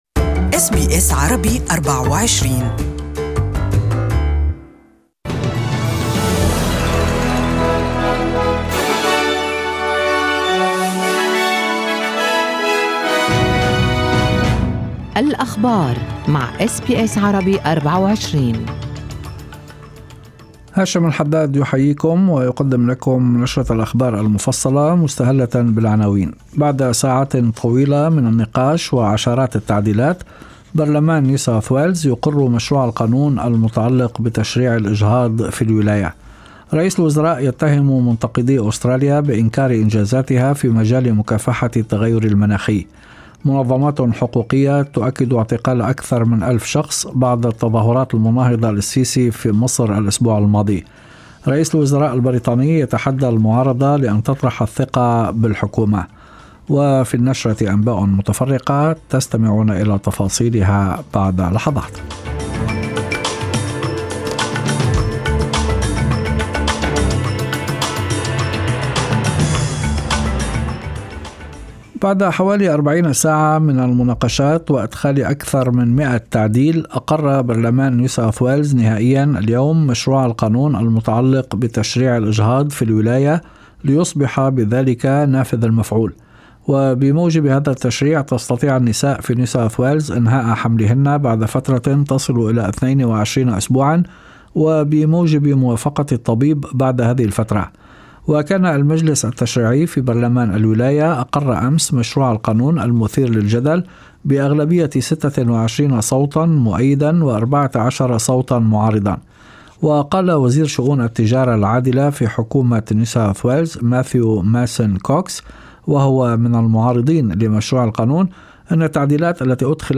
Evening News: Home invasions and carjackings on the rise in Victoria.